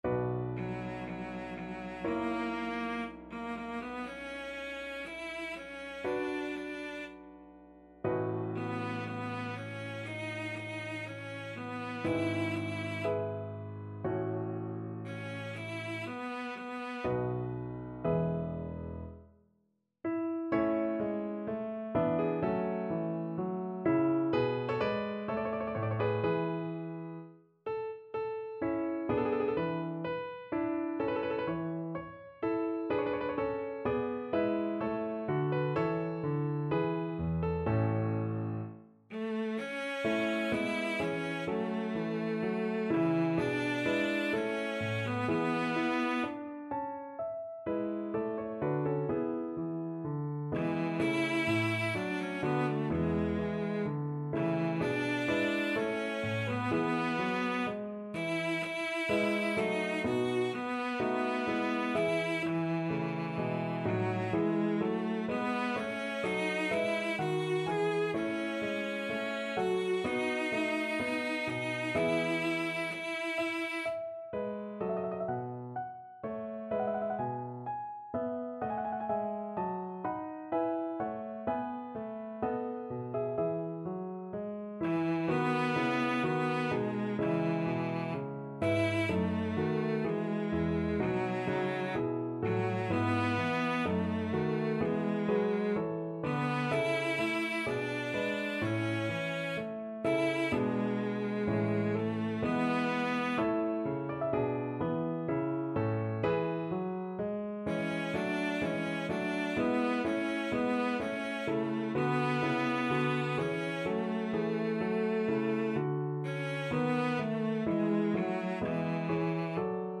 Classical Handel, George Frideric He Was Cut Off; But Thou Didst Not Leave from Messiah Cello version
Cello
G major (Sounding Pitch) (View more G major Music for Cello )
E4-Ab5
4/4 (View more 4/4 Music)
Classical (View more Classical Cello Music)